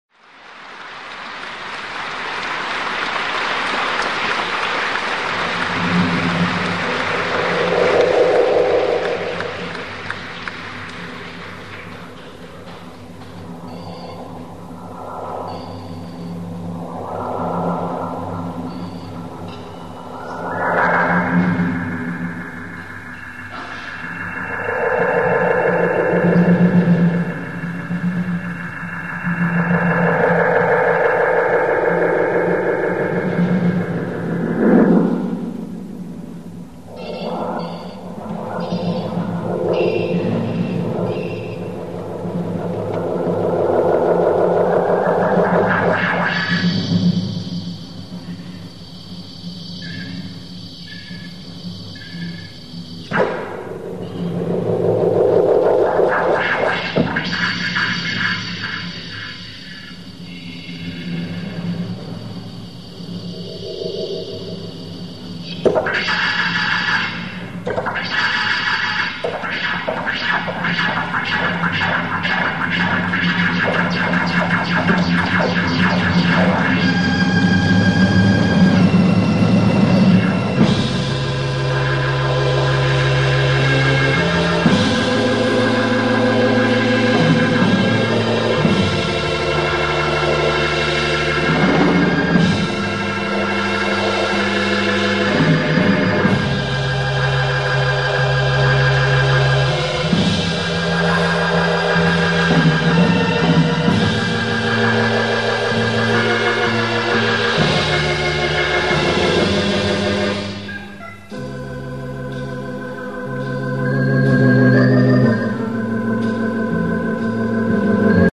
ça fait un peu la guerre des étoiles !
l'orgue Hammond